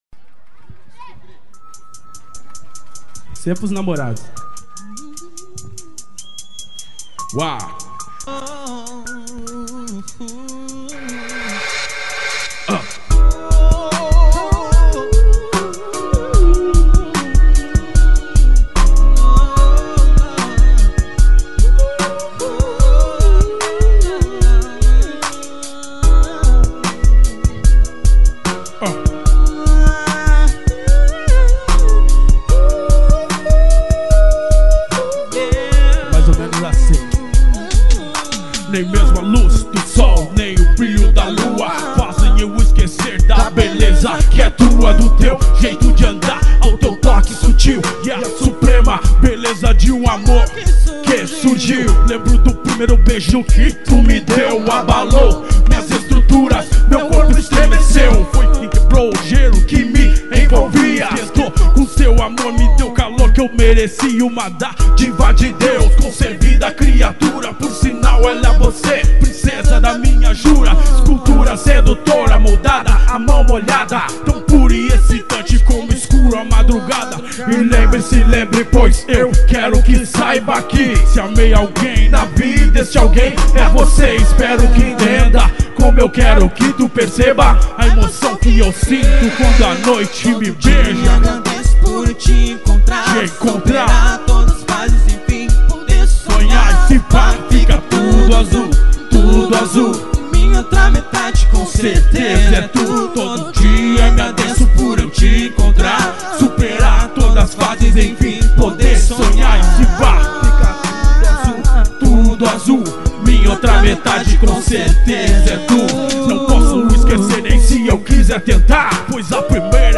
mix ao vivo em são lourenço.